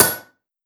Blacksmith hitting hammer 5.wav